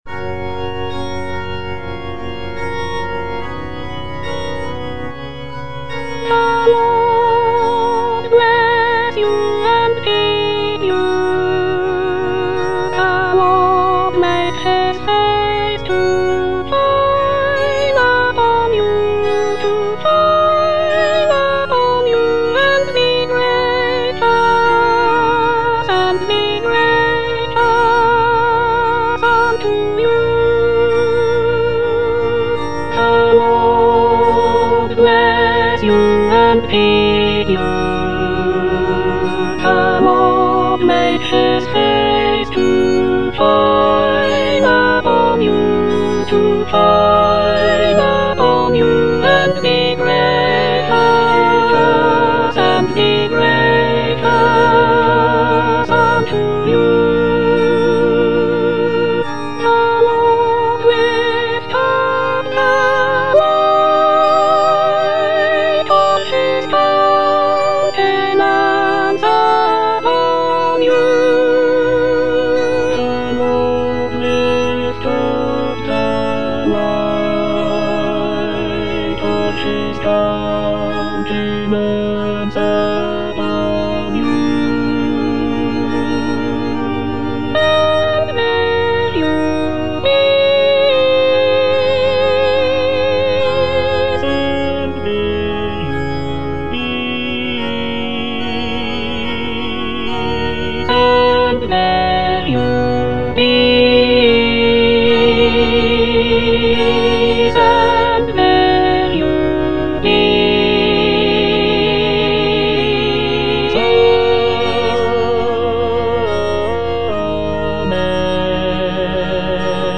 Soprano (Emphasised voice and other voices)
a choral benediction